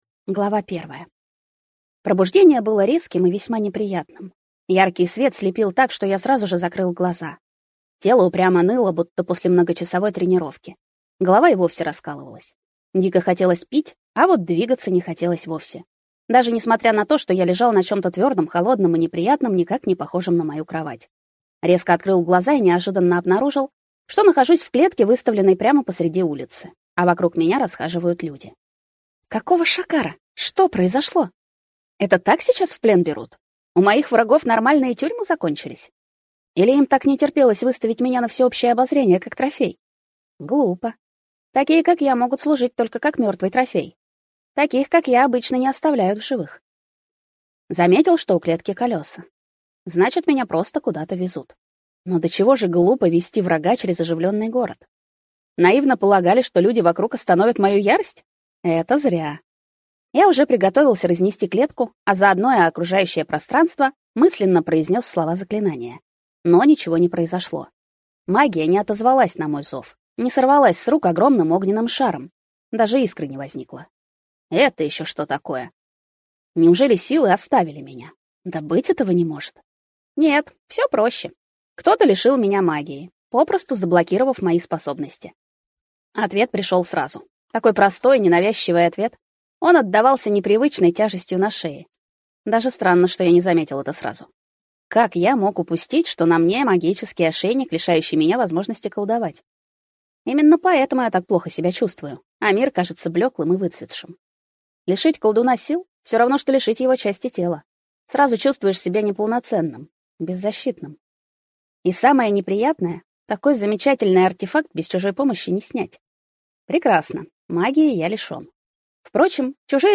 Аудиокнига Хозяйственная госпожа для Темного властелина | Библиотека аудиокниг